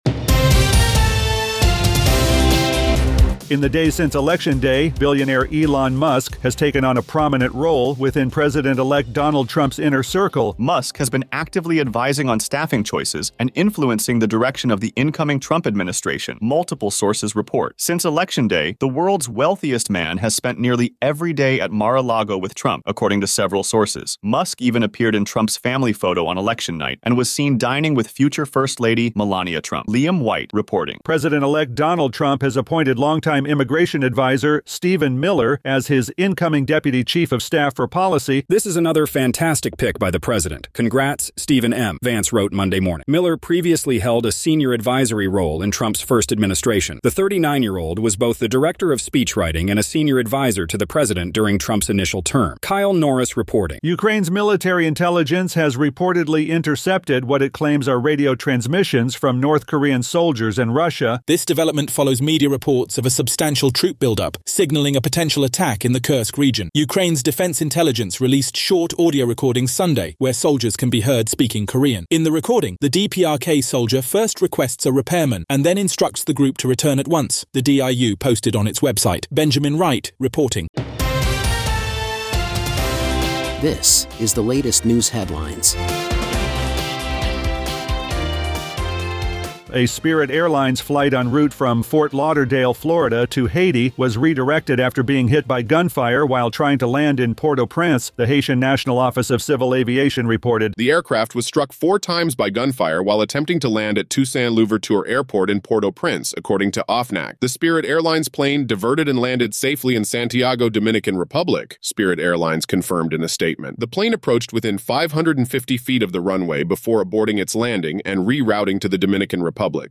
Moderate talk radio with opinions from the left.